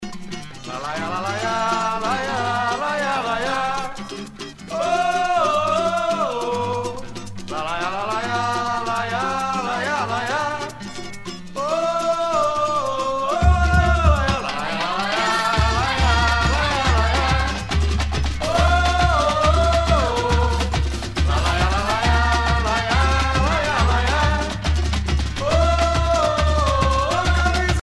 danse : samba
Pièce musicale éditée